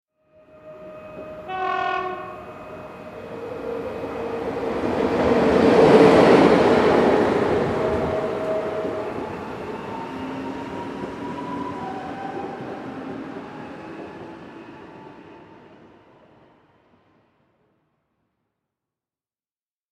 دانلود آهنگ قطار 3 از افکت صوتی حمل و نقل
جلوه های صوتی
دانلود صدای قطار 3 از ساعد نیوز با لینک مستقیم و کیفیت بالا